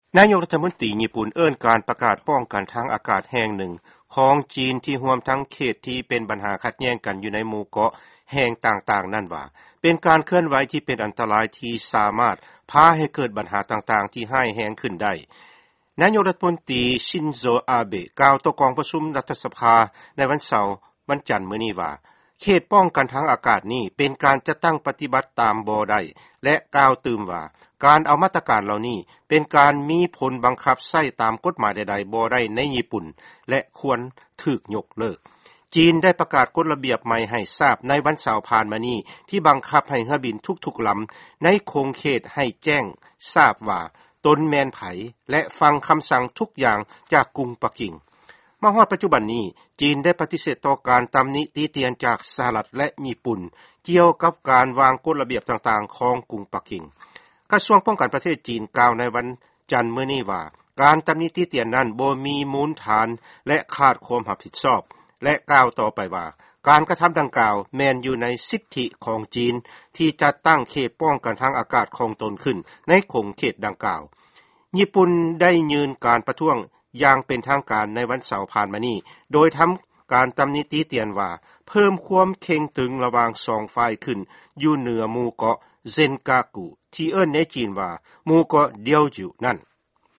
ຟັງຂ່າວ ຈີນ ແລະຍີ່ປຸ່ນ